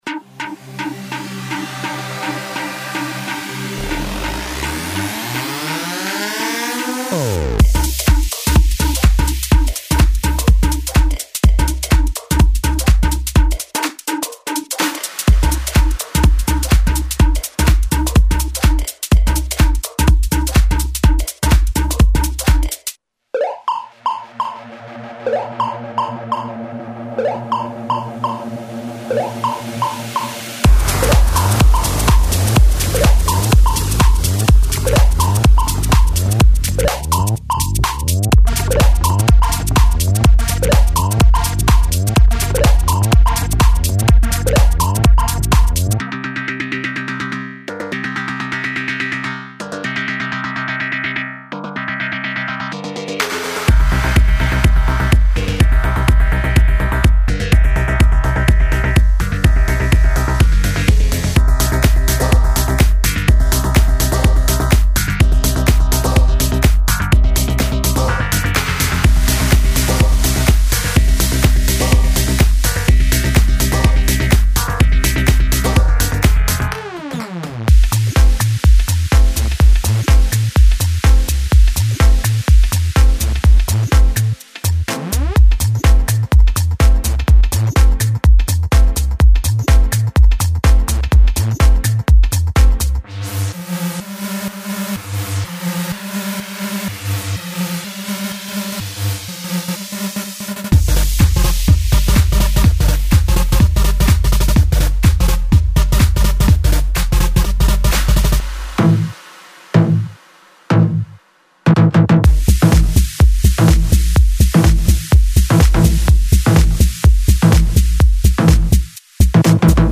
Стиль: Techno